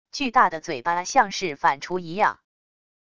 巨大的嘴巴像是反刍一样wav音频